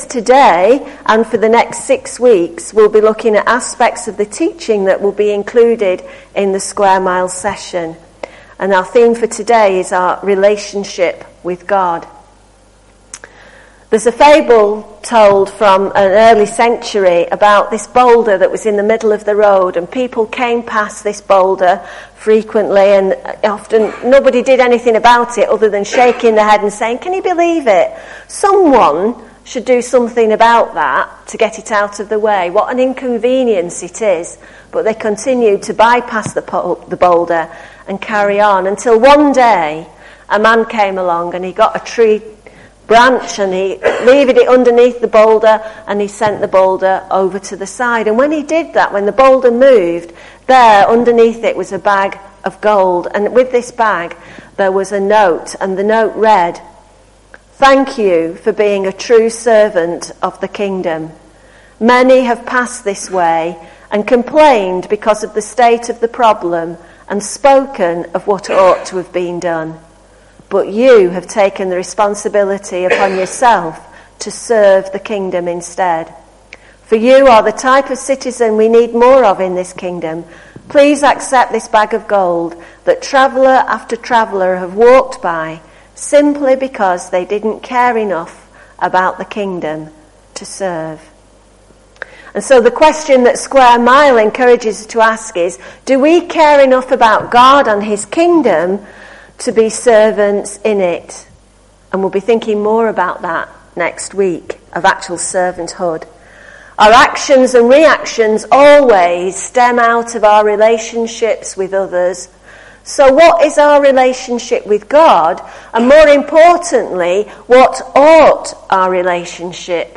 Sermons | Roman Road IM Church Failsworth